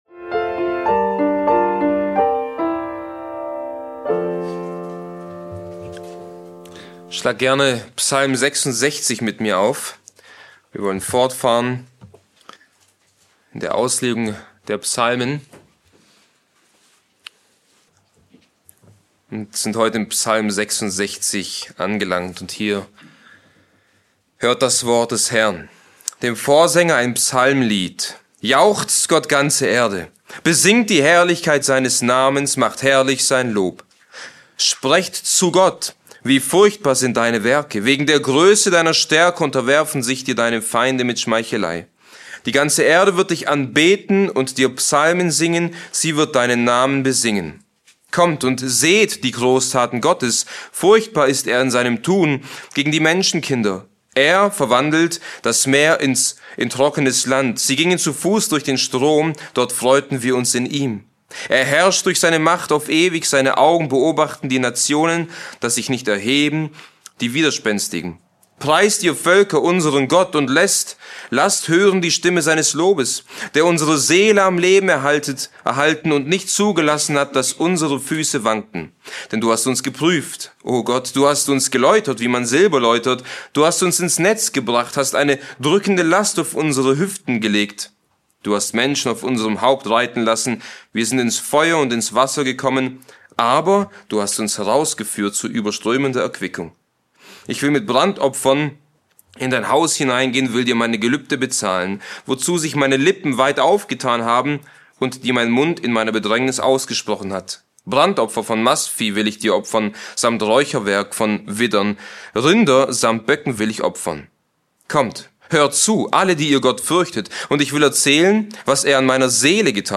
Die Predigt ruft dazu auf, Gottes Großtaten zu bestaunen und ihn dafür anzubeten.